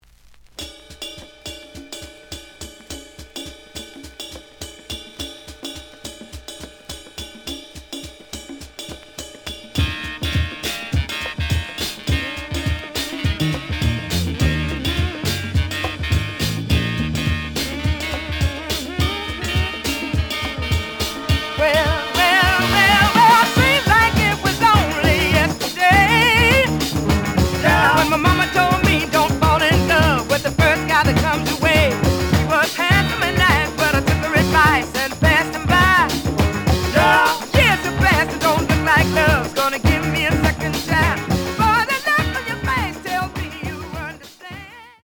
The audio sample is recorded from the actual item.
●Genre: Funk, 70's Funk